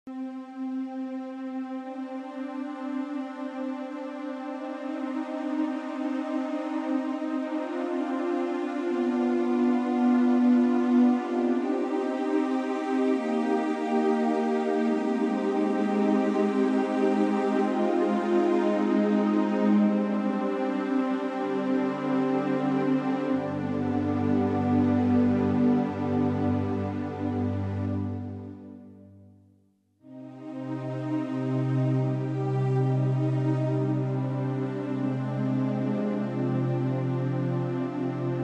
Slow, deep music echoes the despair as you witness the fall of civilization.